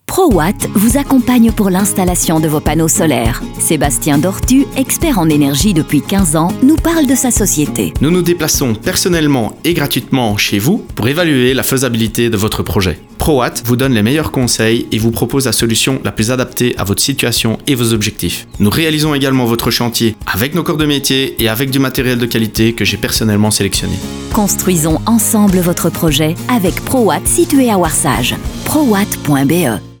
DES PUBS AUDIOS
Plus qu’un spot avec une bande sonore ordinaire, nous créons un univers sonore unique et distinct pour chaque spot avec des effets, des bruitages immersifs et une musique adaptée à votre production.